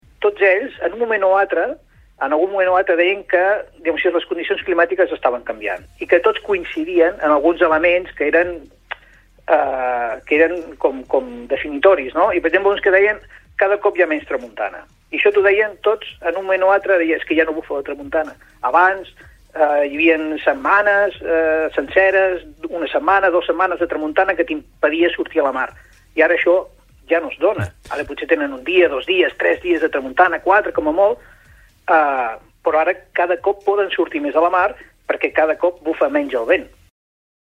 Entrevistes SupermatíNotíciesPalamós